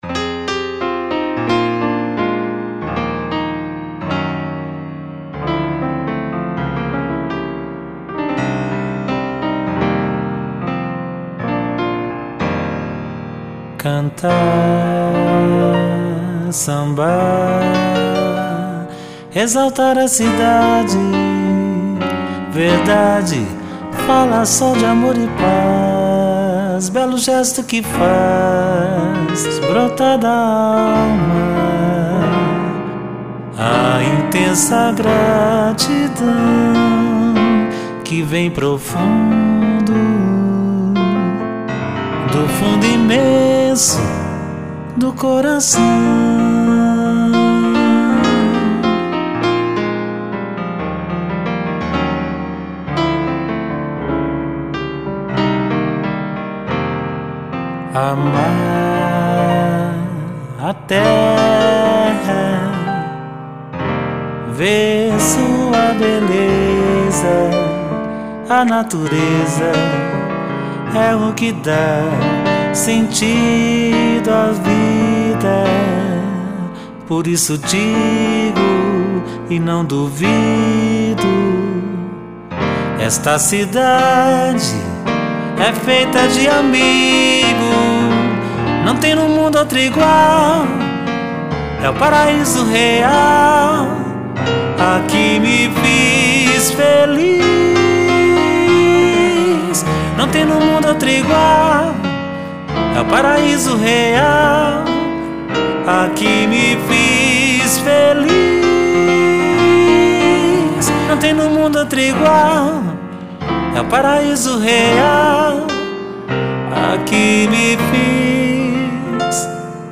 Piano
Samba-pra-Cidade-piano-e-voz.mp3